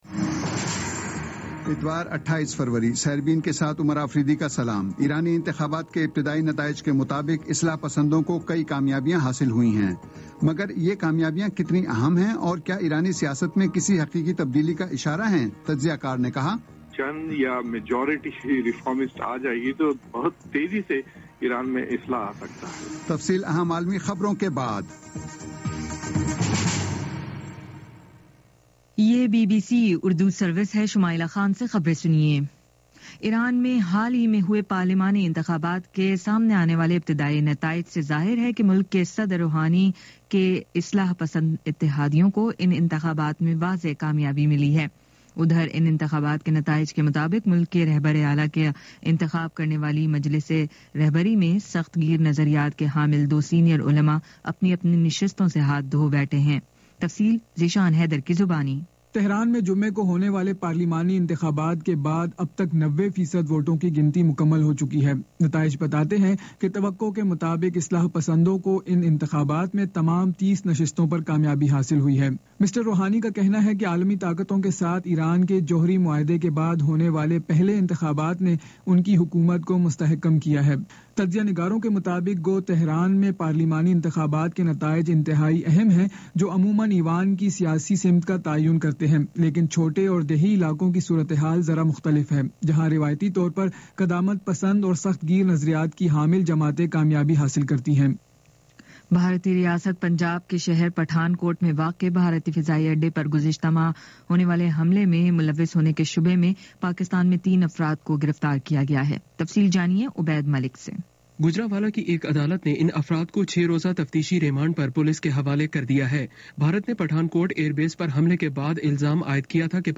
اتوار 28 فروری کا سیربین ریڈیو پروگرام